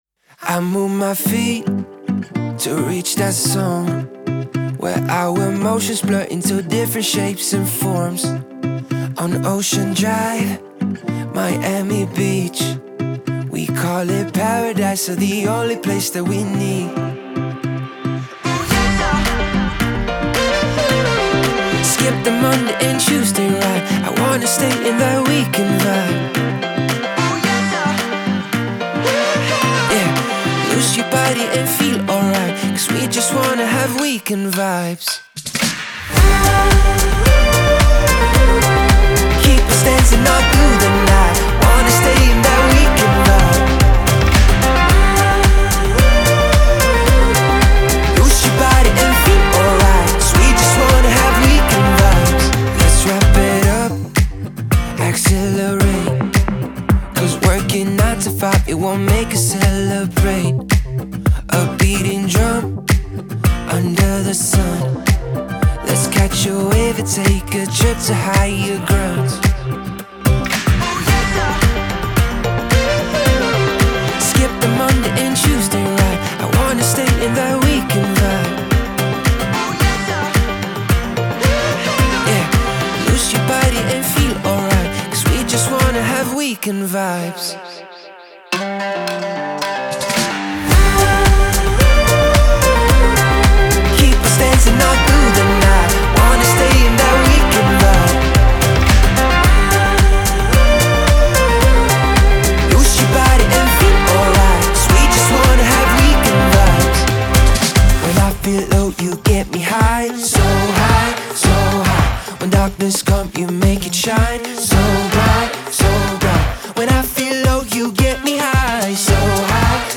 это яркая и энергичная трек в жанре электронной поп-музыки